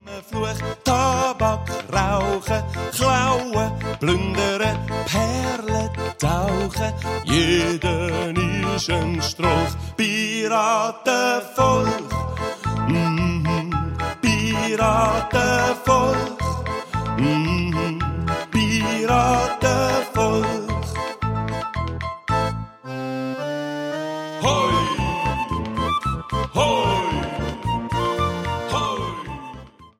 Mundartlieder für Chinderchile